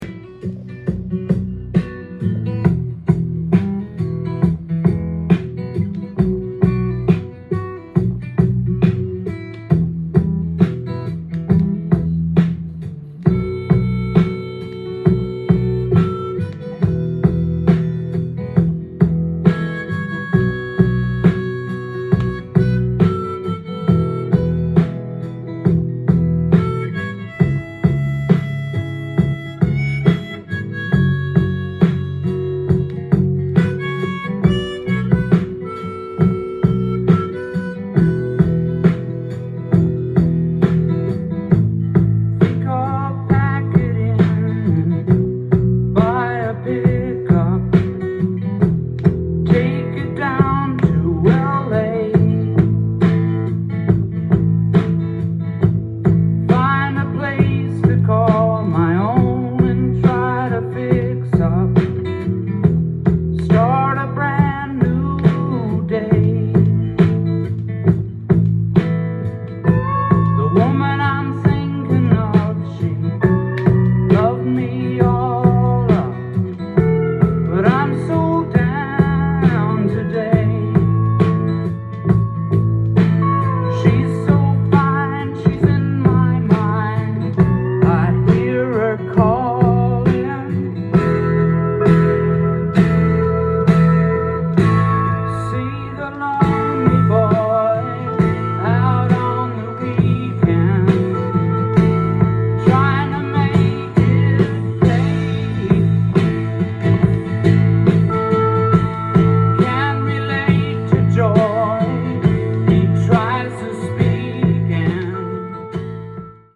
店頭で録音した音源の為、多少の外部音や音質の悪さはございますが、サンプルとしてご視聴ください。
インサート無し　プチプチ音有り